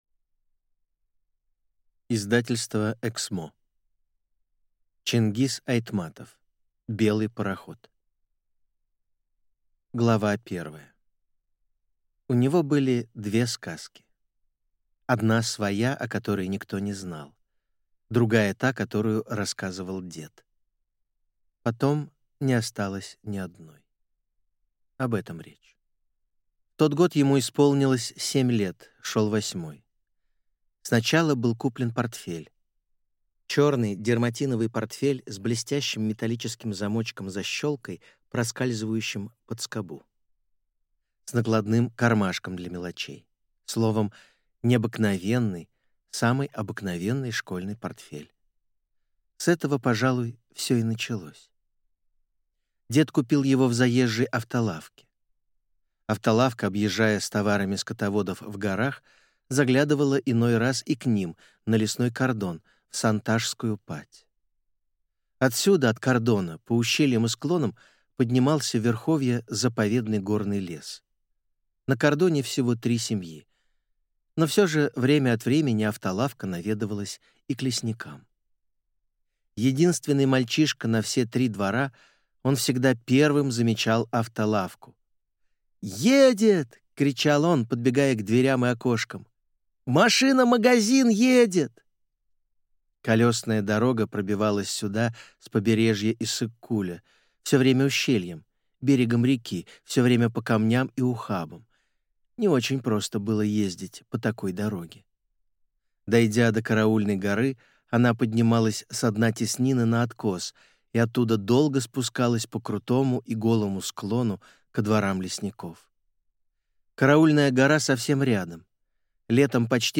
Аудиокнига Белый пароход | Библиотека аудиокниг